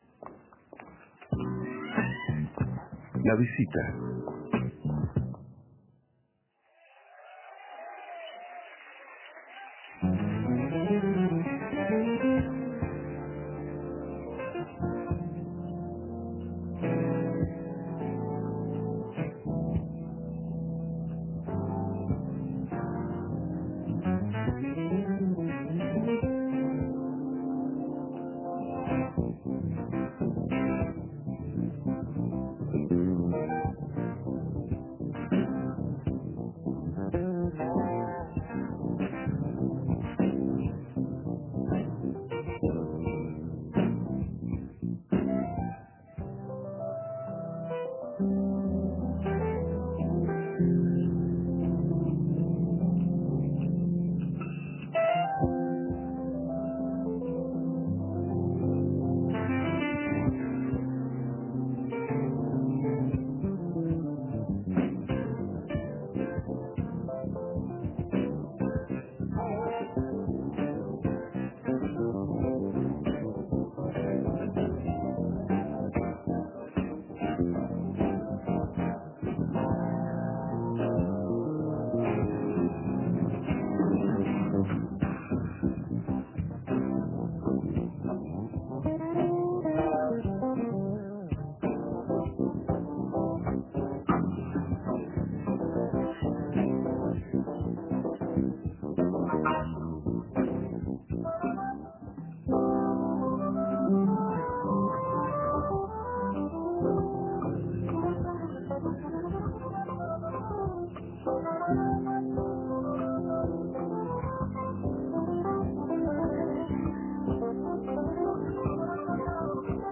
Escuche la entrevista a Hugo Fattoruso.